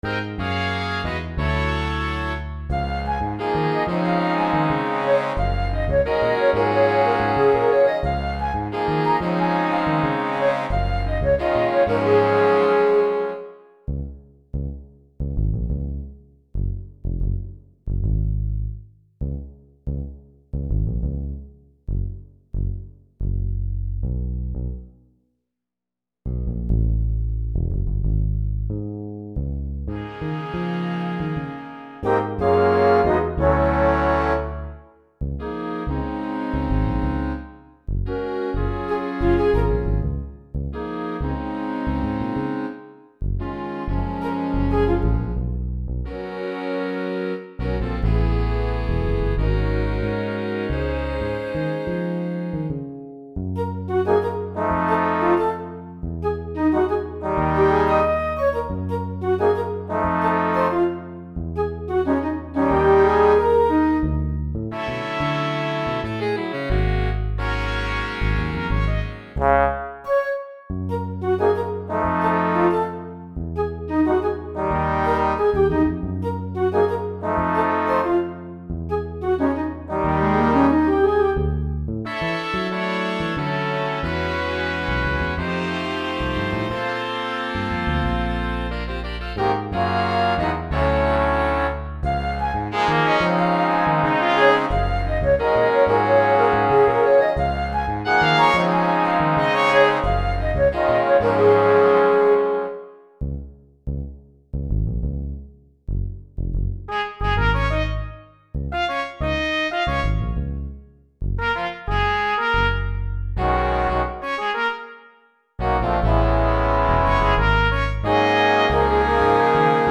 Flöjt 1      Flöjt 2       Altflöjt
Horn 1       Horn 2
Trumpet 1    Trumpet 2     Trumpet 3    Trumpet 4